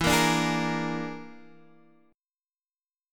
E7b5 chord